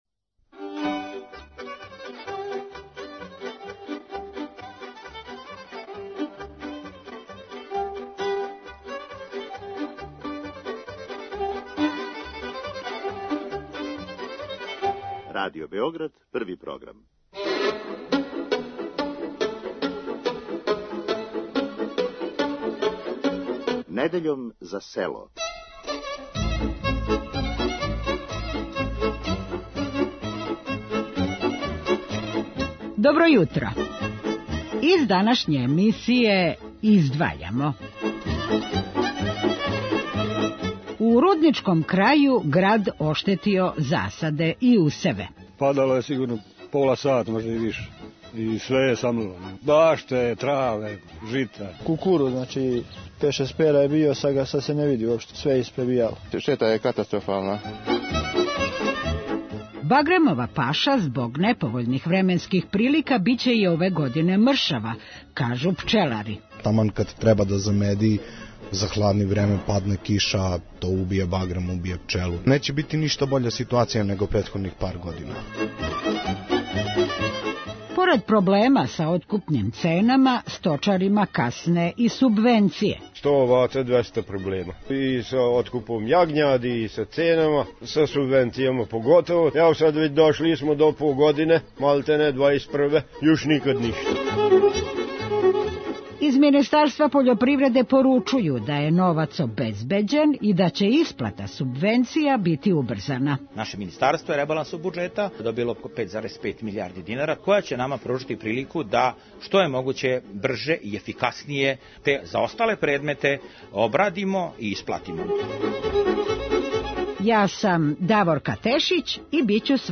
Аграрна политика, пољопривреда и живот на селу, то су теме емисије Недељом за село која се емитује нa таласима Првог програма Радио Београда од 16. јуна 1946. године.